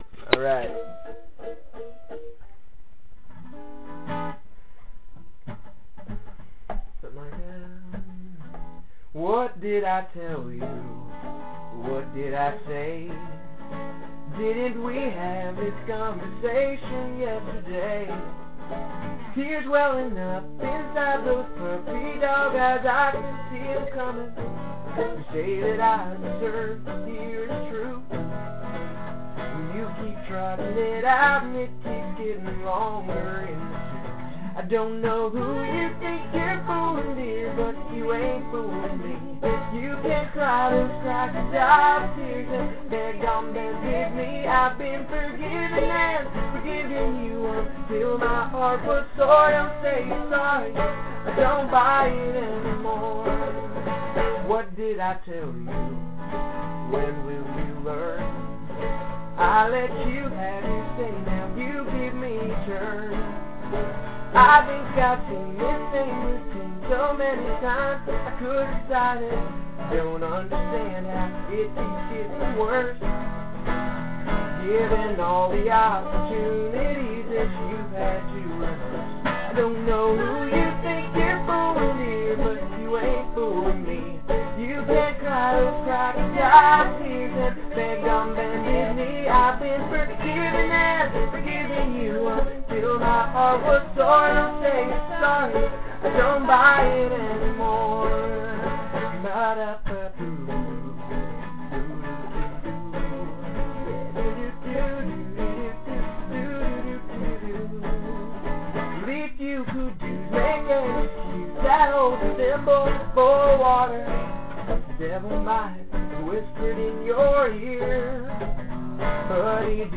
Don't Say You're Sorry (Practice)- MP3
So this is my second country song for the year.